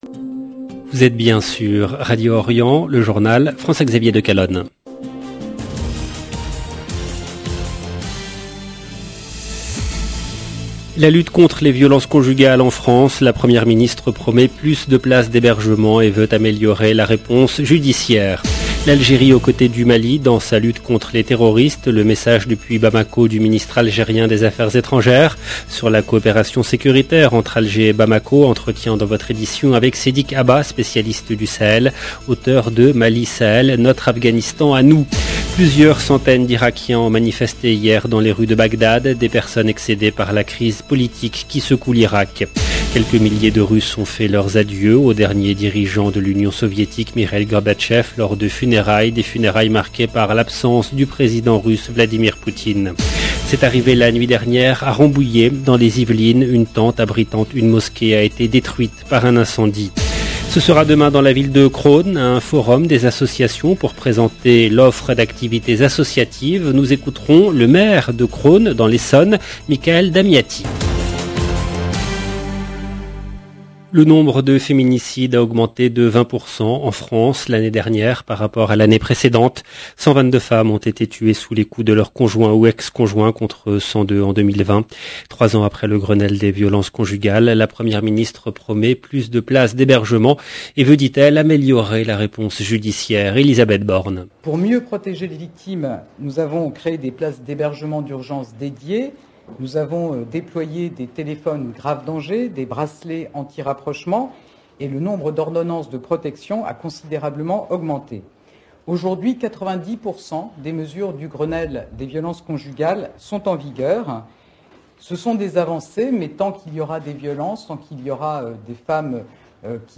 EDITION DU JOURNAL DU SOIR EN LANGUE FRANCAISE DU 3/9/2022
Nous écouterons le maire de Crosne Michaël Damiati. 0:00 17 min 13 sec